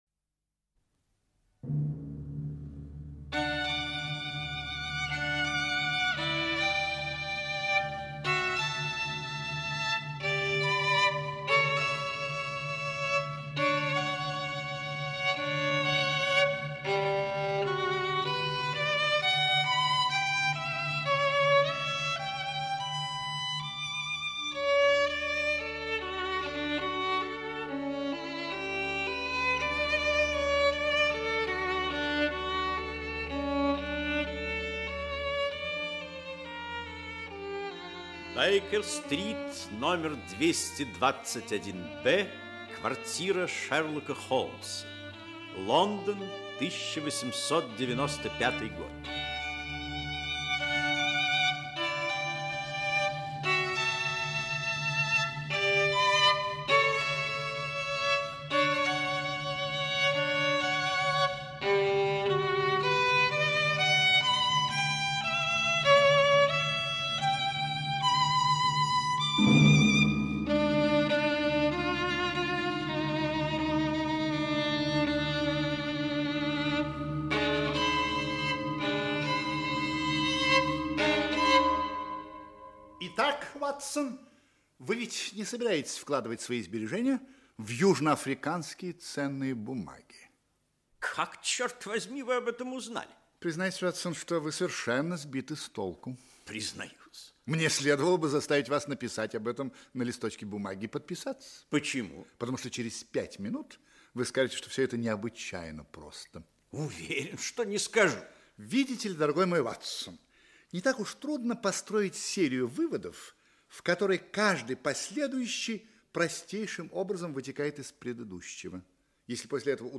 Аудиокнига Пляшущие человечки. Часть 1 | Библиотека аудиокниг
Часть 1 Автор Артур Конан Дойл Читает аудиокнигу Актерский коллектив.